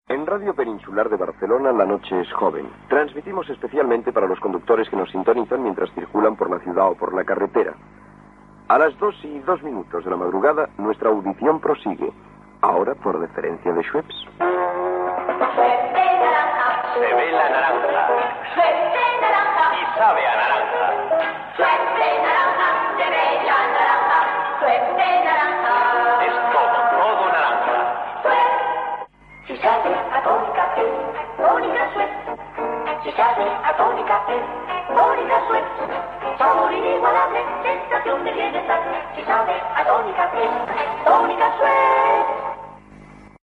Hora i publicitat